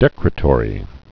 (dĕkrĭ-tôrē, dĭ-krētə-rē)